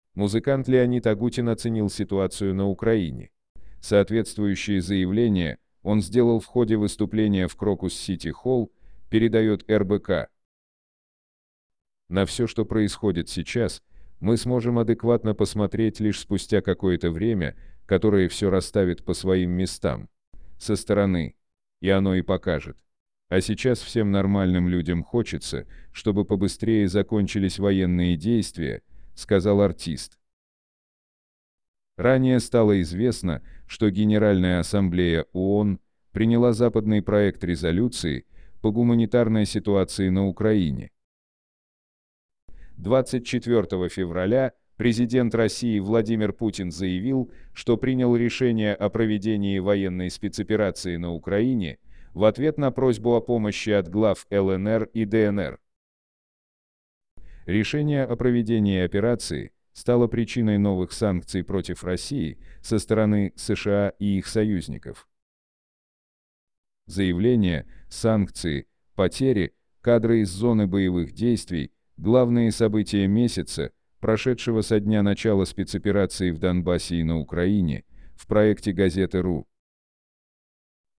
Музыкант Леонид Агутин оценил ситуацию на Украине. Соответствующее заявление он сделал в ходе выступления в Crocus City Hall, передает РБК .